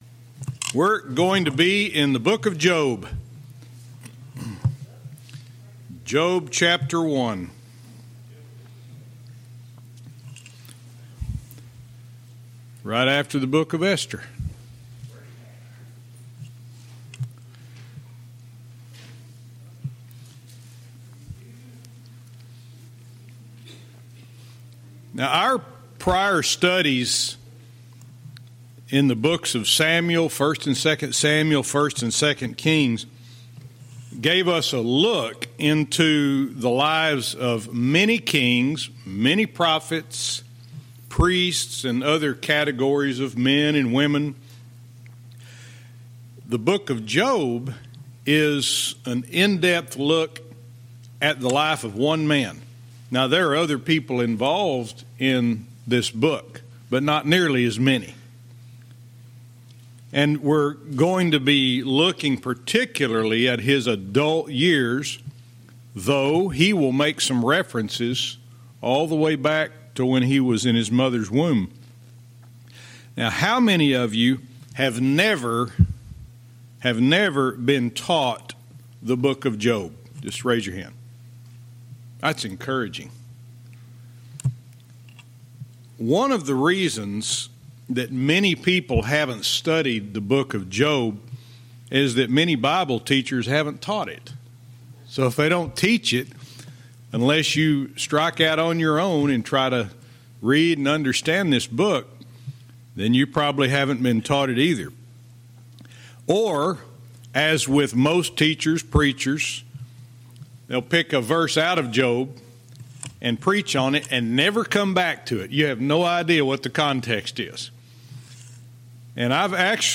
Verse by verse teaching - Job 1:1